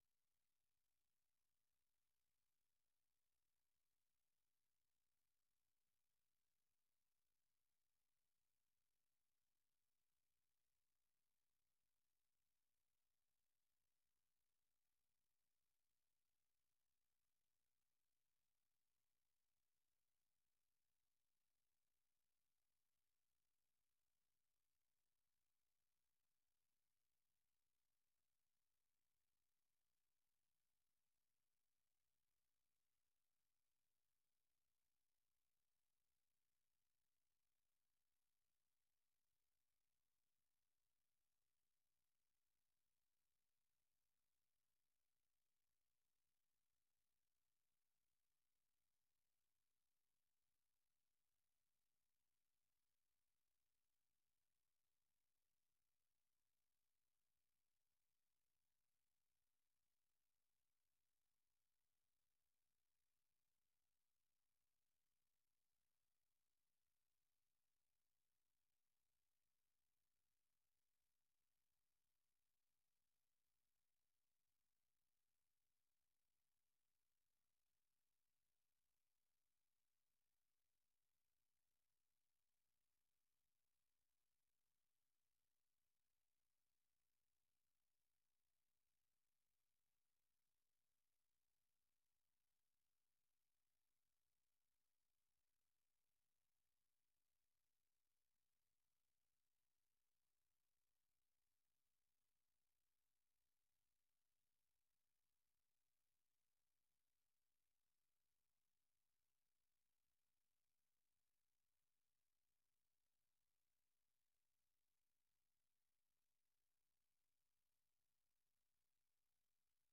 Listen Live - 粵語廣播 - 美國之音